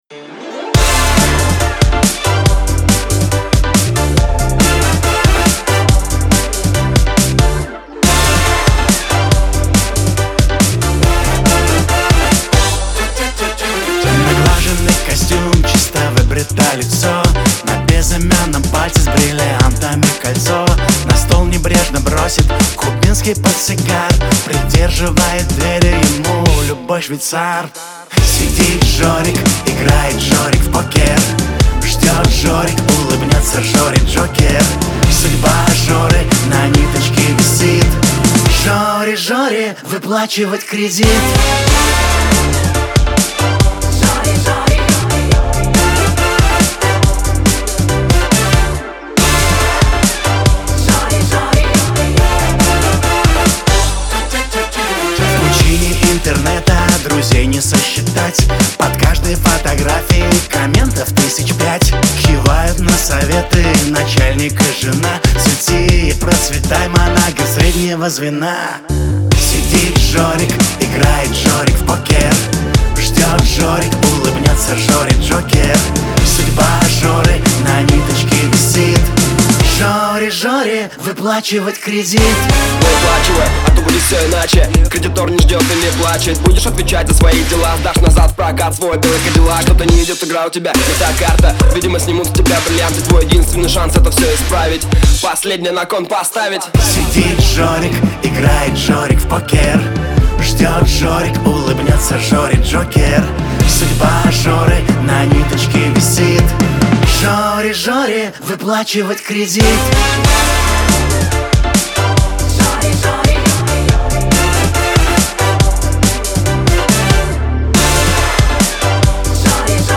используя запоминающиеся мелодии и ритмичные аранжировки.